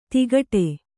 ♪ tigaṭe